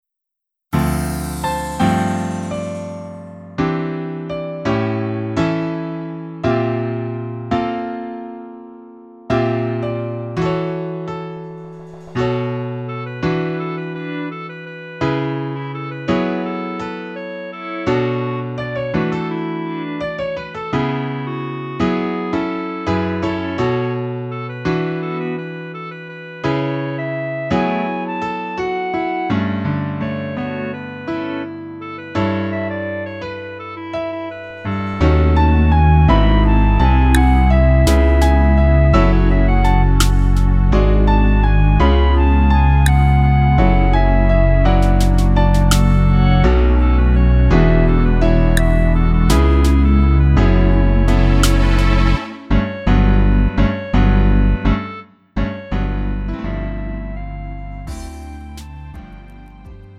음정 -1키 3:57
장르 가요 구분 Lite MR
Lite MR은 저렴한 가격에 간단한 연습이나 취미용으로 활용할 수 있는 가벼운 반주입니다.